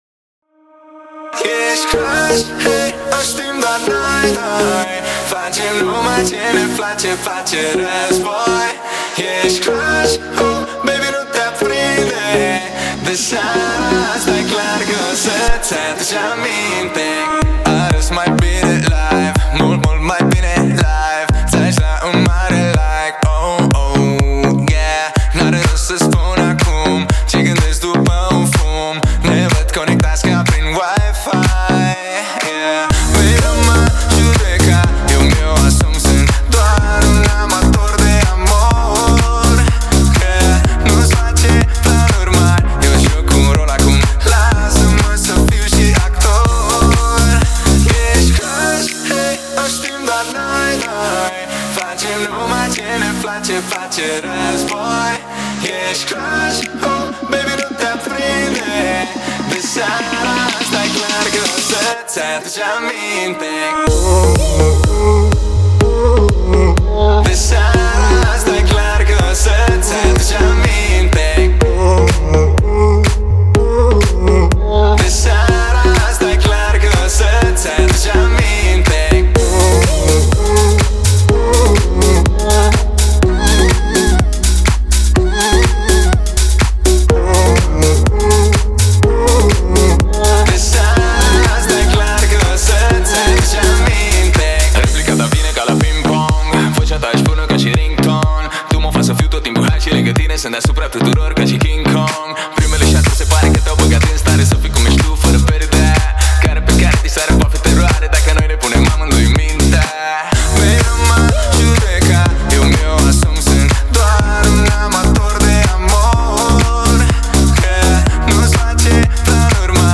это яркая и зажигательная песня в жанре EDM и поп